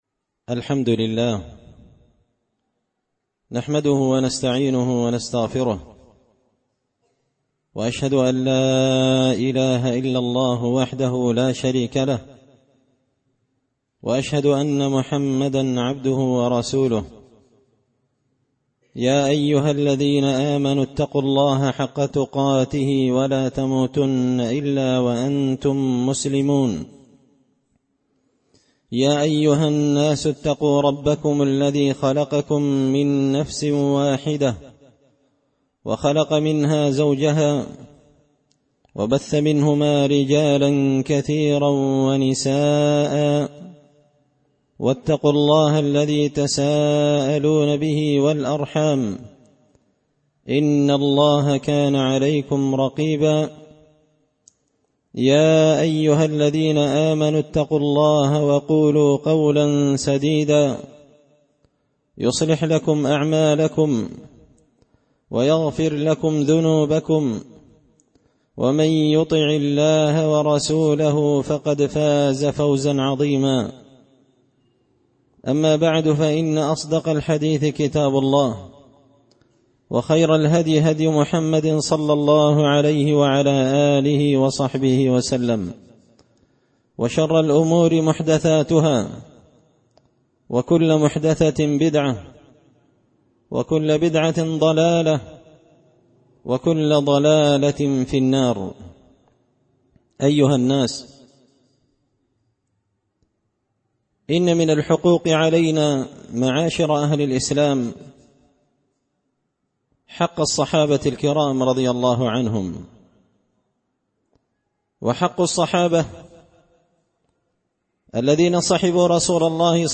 خطبة جمعة بعنوان – حق الصحابة رضي الله عنهم
دار الحديث بمسجد الفرقان ـ قشن ـ المهرة ـ اليمن